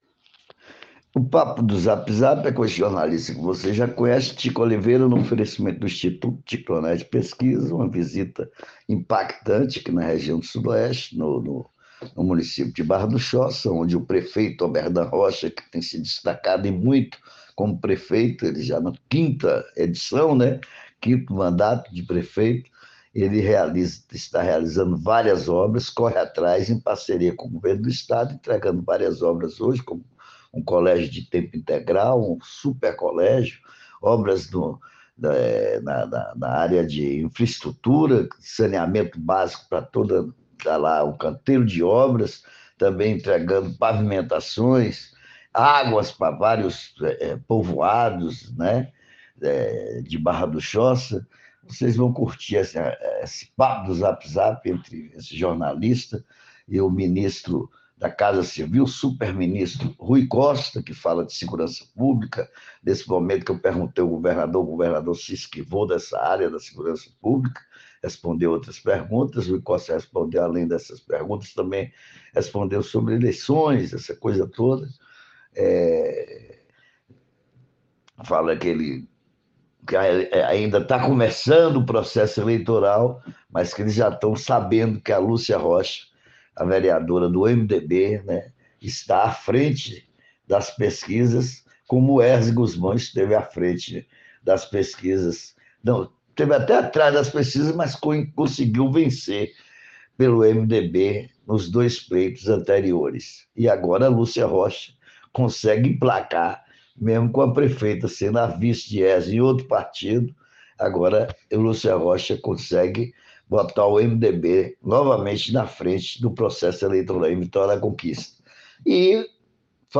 Ouça o podcast com o ministro Rui e com o governador Jerônimo Rodrigues: